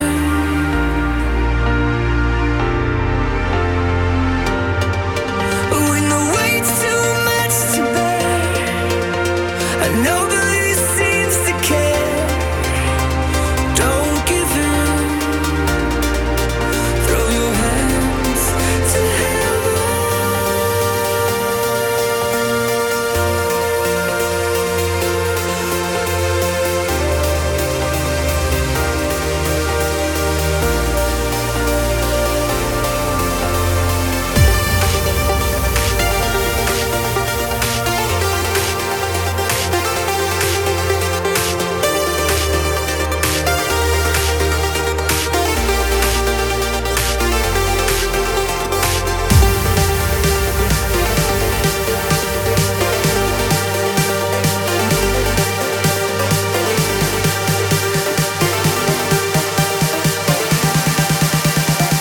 • Качество: 256, Stereo
мужской голос
громкие
Electronic
Trance
vocal trance